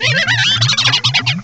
cry_not_toxicroak.aif